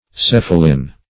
cephalin - definition of cephalin - synonyms, pronunciation, spelling from Free Dictionary
cephalin \ceph"a*lin\ (k[e^]f"[.a]*l[i^]n), n. [Gr. kefalh` the